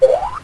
backpack_open.ogg